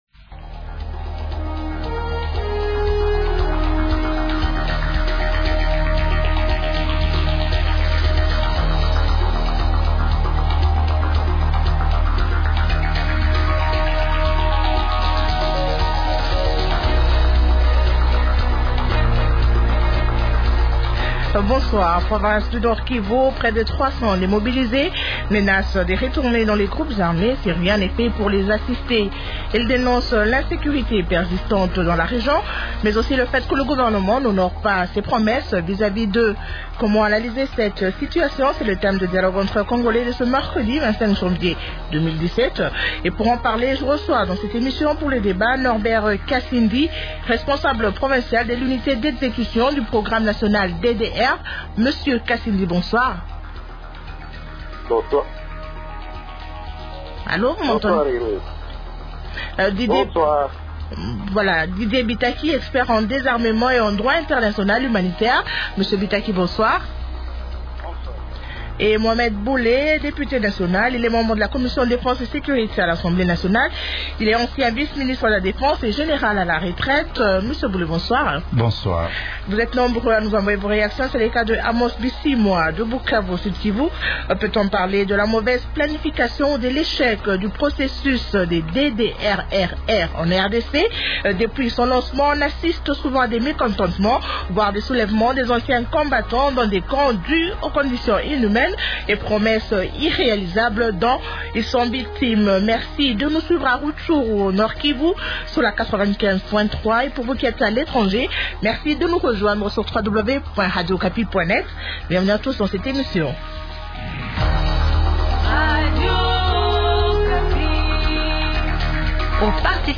Participent au débat de ce soir :